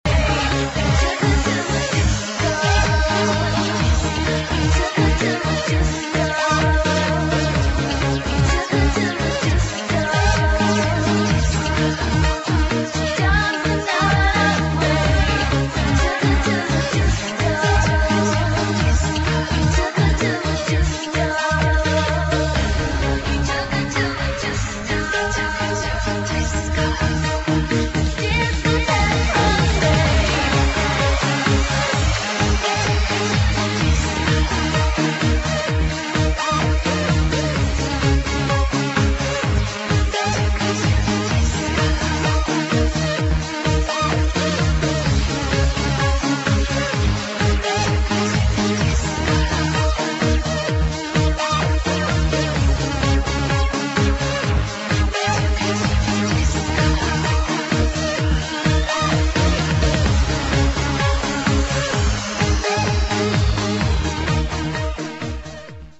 [ HOUSE / ELECTRO POP ]